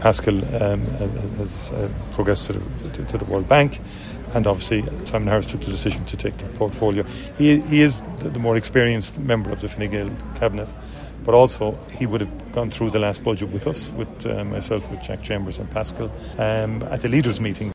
Speaking in Johannesburg this afternoon, Micheal Martin said his Cabinet colleague is vastly experienced: